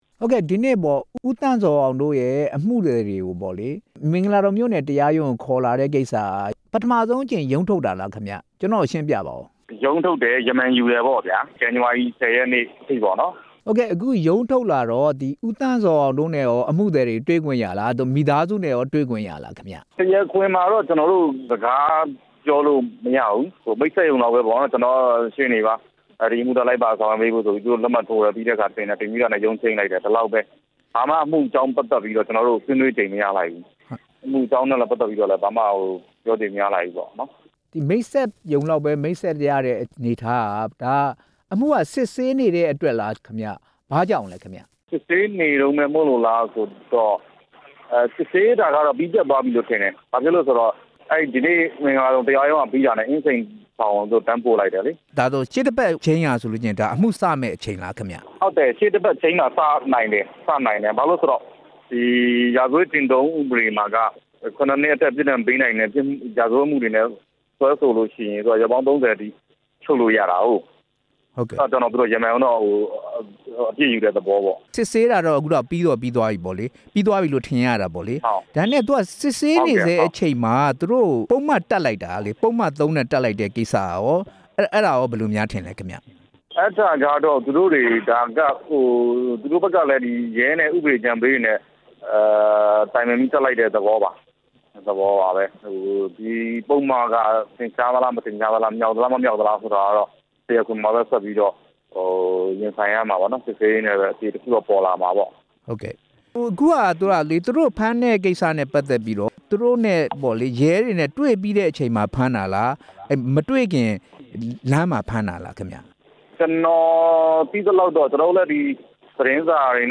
ဖမ်းဆီးခံရိုက်တာ သတင်းထောက်နှစ်ဦးရဲ့ ရှေ့နေနဲ့ မေးမြန်းချက်